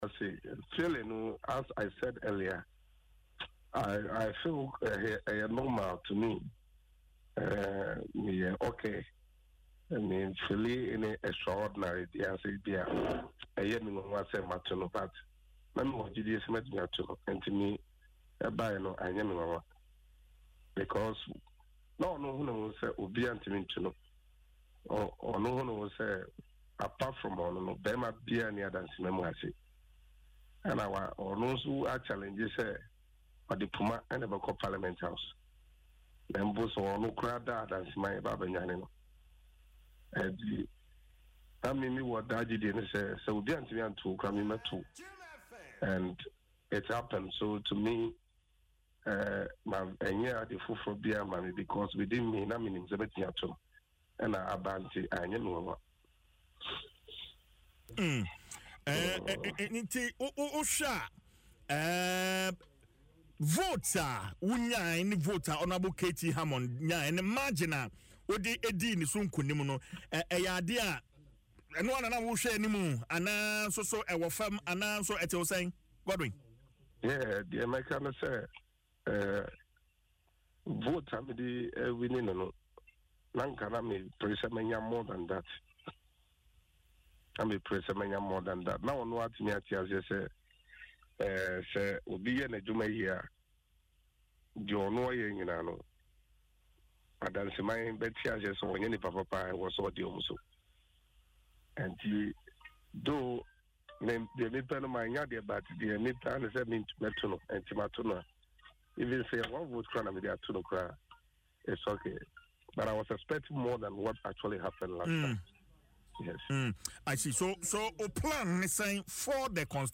But in an interview on Adom FM’s morning show Dwaso Nsem, he said though he appreciates constituents for the votes, he expected a wider margin.